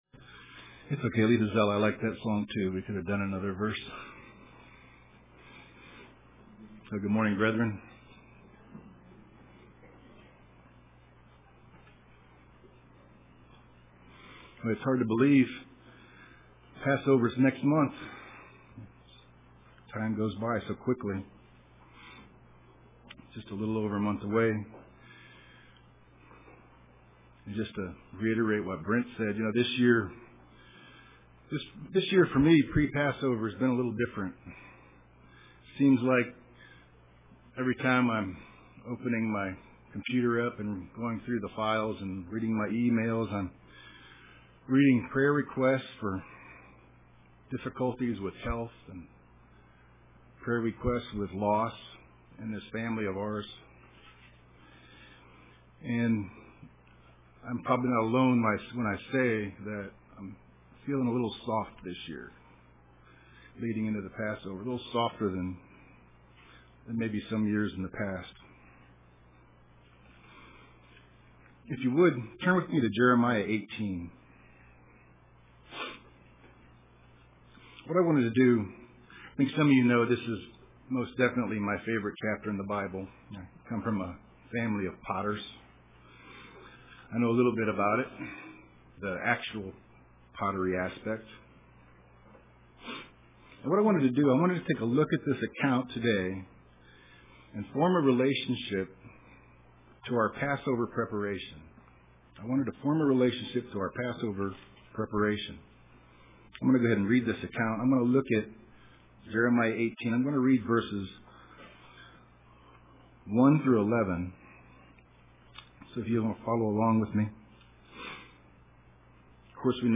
Print Be Formed Like Clay UCG Sermon